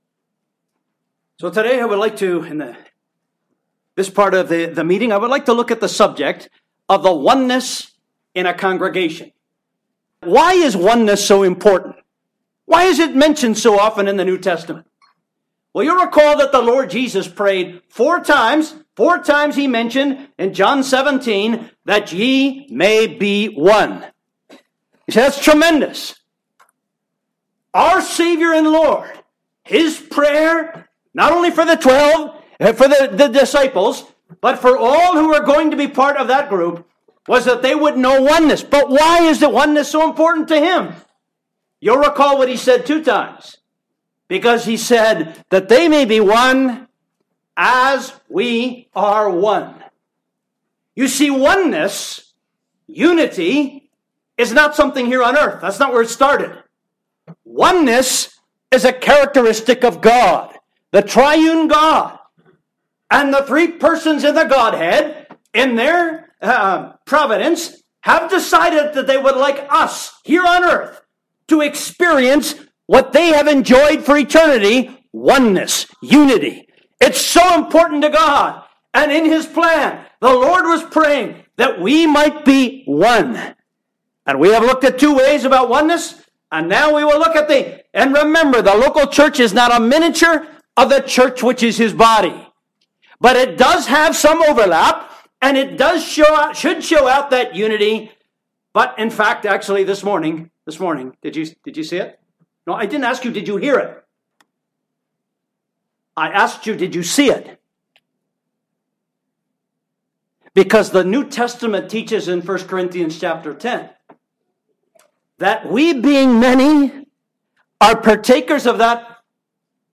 (Recorded at the Hickory Gospel Hall conference, NC, USA, 2019)